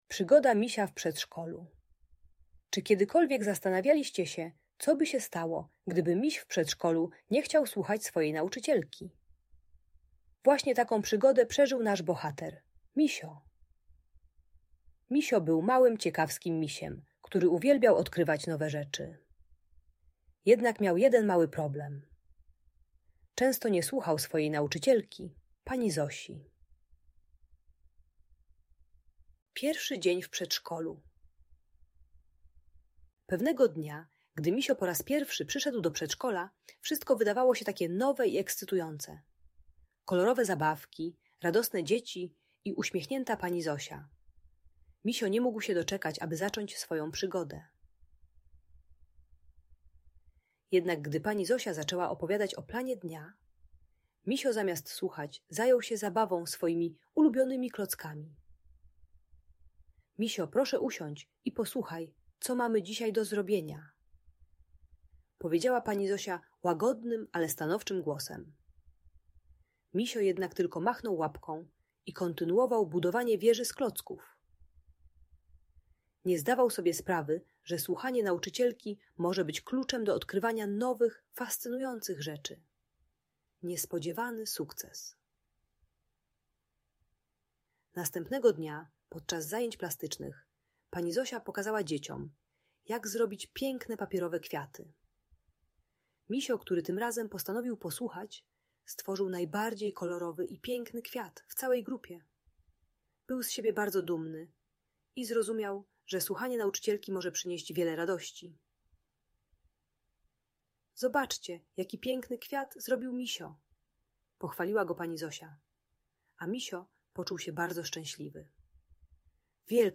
Przygoda Misia w Przedszkolu - historia o nauce słuchania - Audiobajka